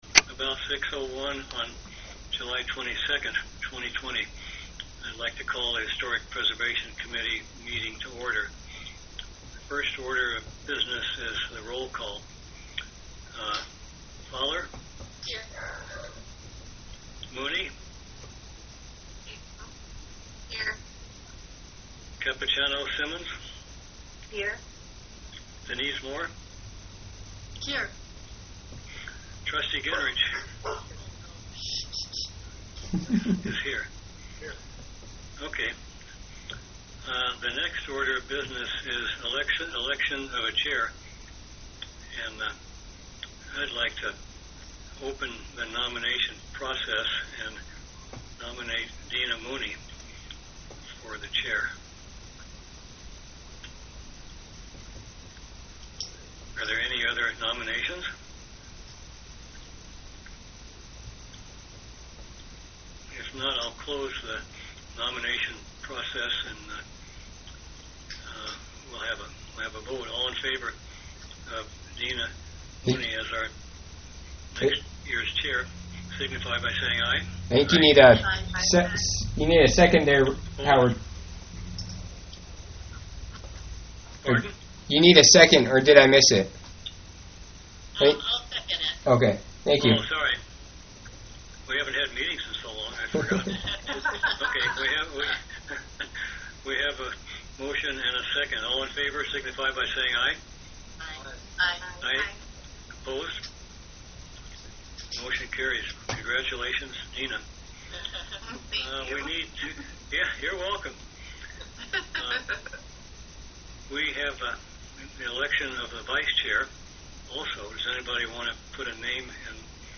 July 22, 2020 (virtual meeting) - Village of Allouez
HISTORIC PRESERVATION COMMITTEE MEETING WEDNESDAY, JULY 22, 2020 6:00 PM, ALLOUEZ VILLAGE HALL